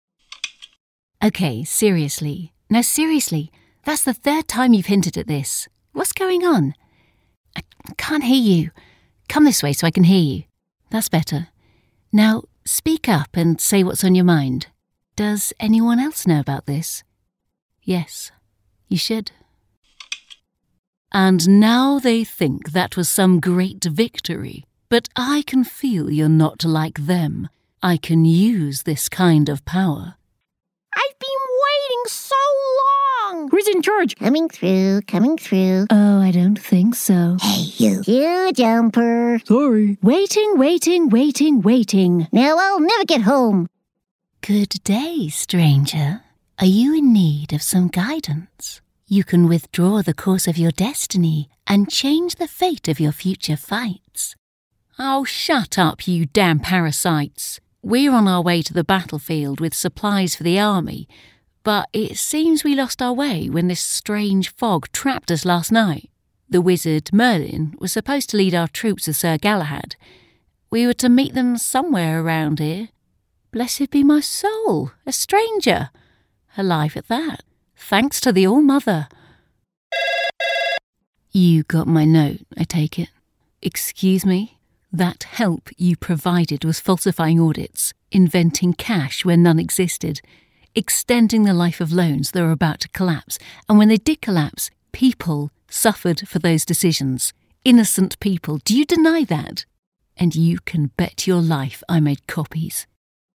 Video Games
I have my own studio where I record and edit to the highest professional standards, offering live direction where required.
Rode NT-1A microphone
Young Adult
Middle-Aged
Mezzo-SopranoSoprano